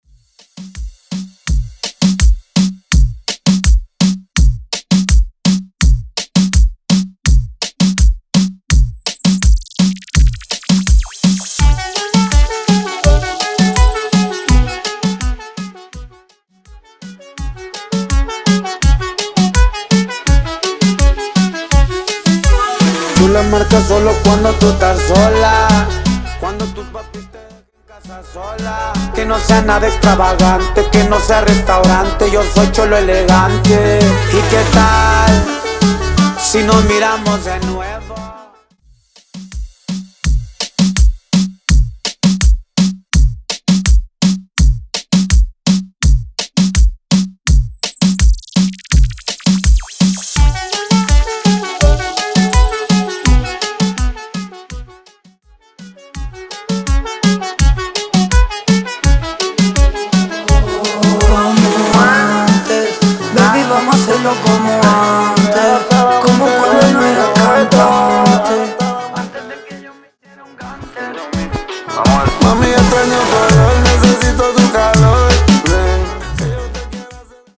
Intro Dirty, Pre Coro